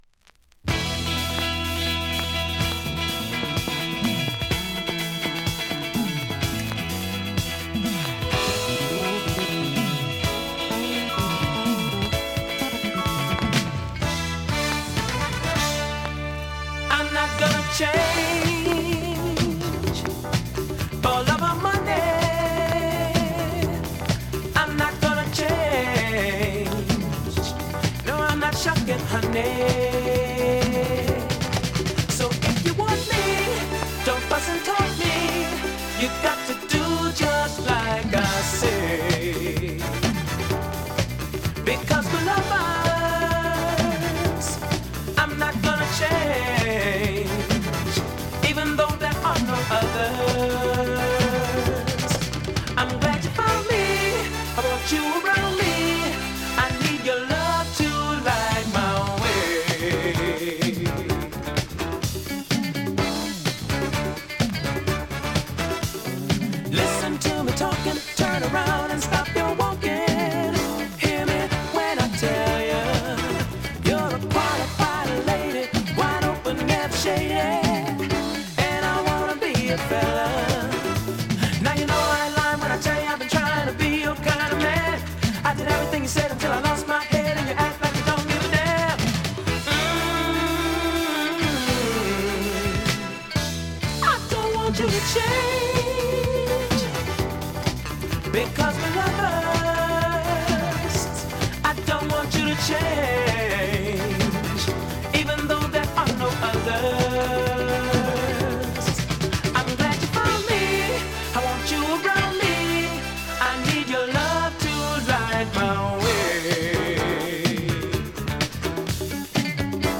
◆盤質Ａ面/VG+◆盤質Ｂ面/VG+